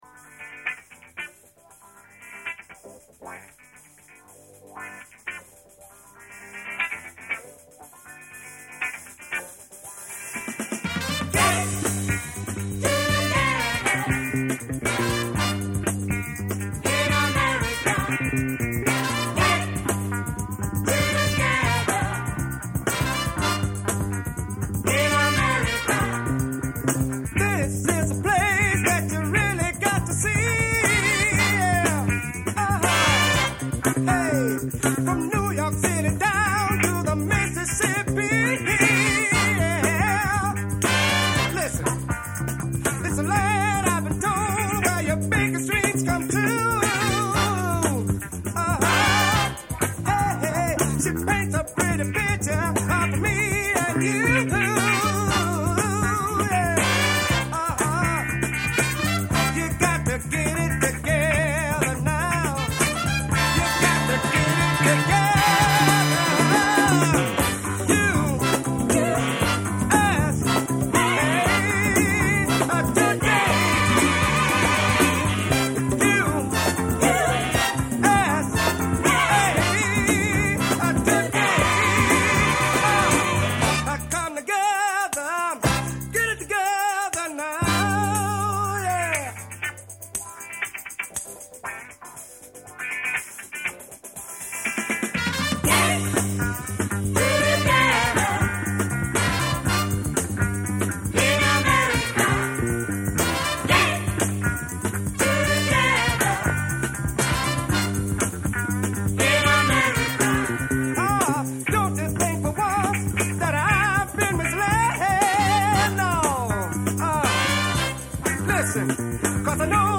Morceau funk 70 : qui chante ?